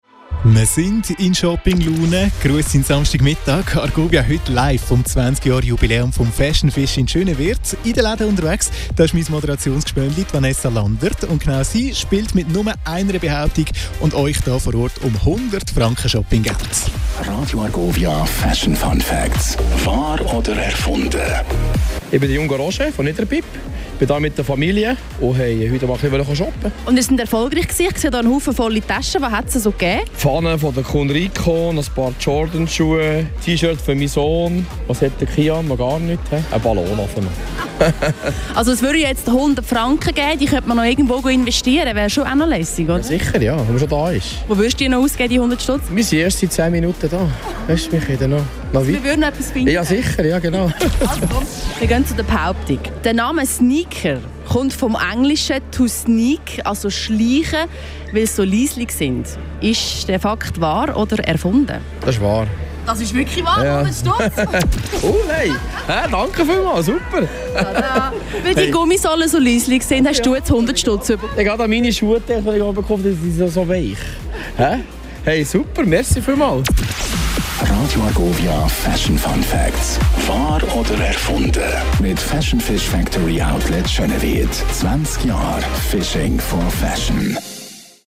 Radio Argovia live bei Fashion Fish 27.09.2025